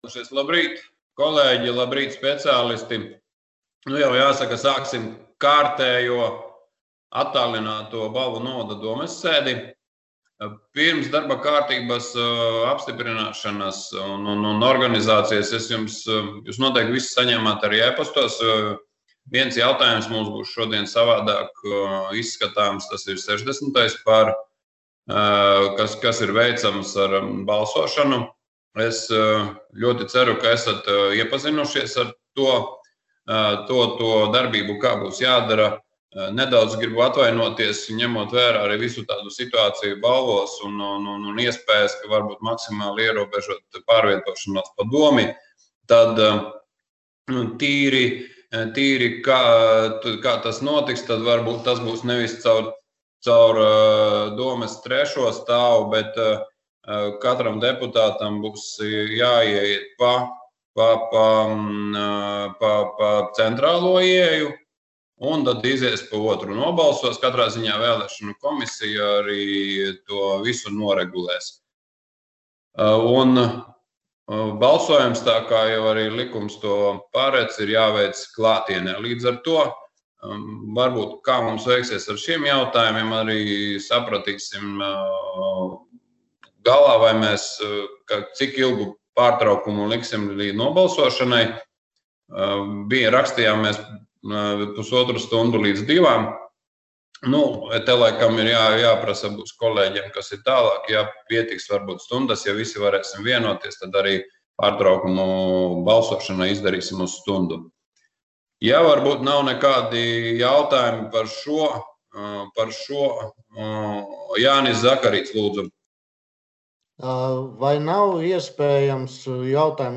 25. februāra domes sēde